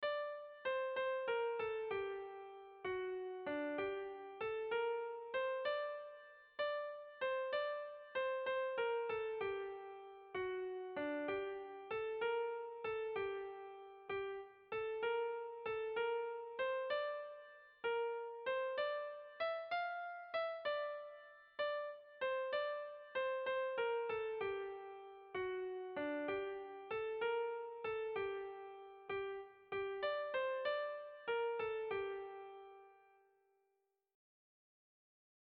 Irrizkoa
Zortziko txikia (hg) / Lau puntuko txikia (ip)
A1A2BA1